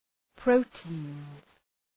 Προφορά
{‘prəʋti:nz}